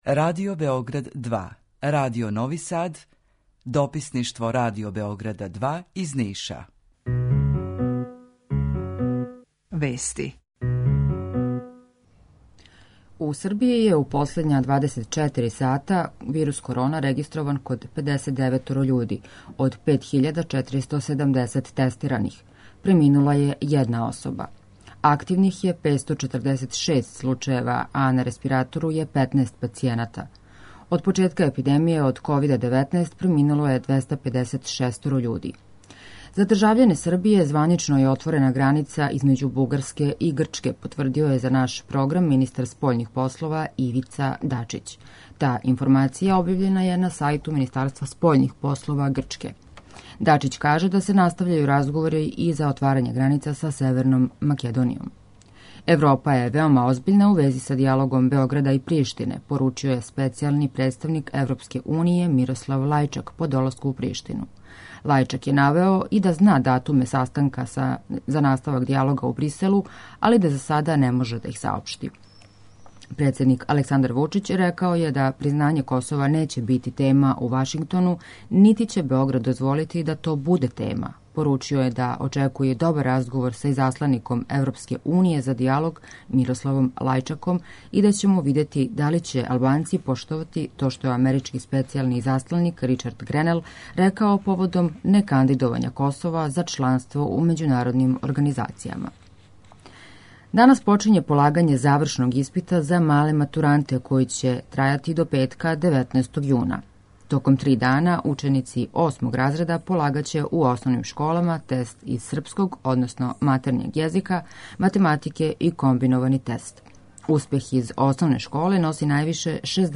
Укључење Радио Грачанице
Јутарњи програм из три студија